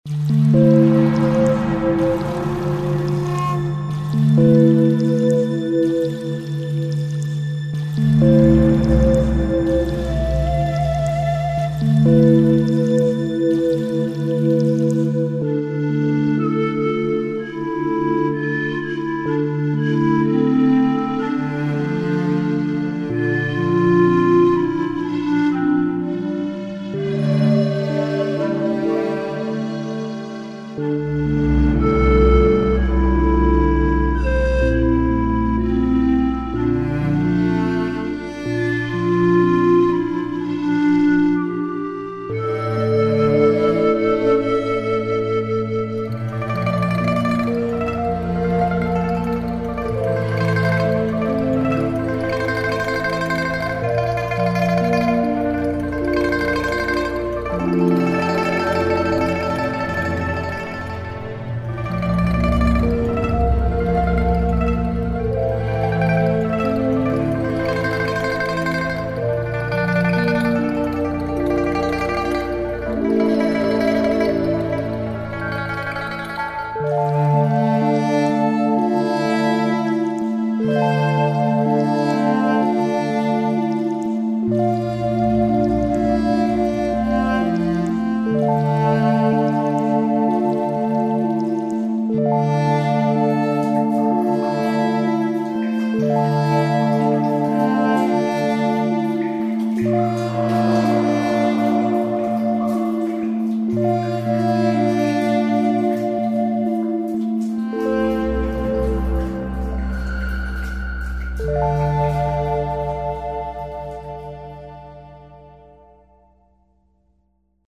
诡异阴冷中带着阵阵肃杀的气息